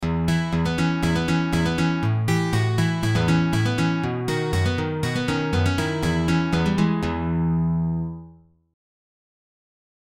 BY PAUL GILBERT > ROCKABILLY BASS PATTERNS
ROCKABILLY+BASS+PATTERNS.mp3